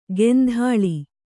♪ gendhāḷi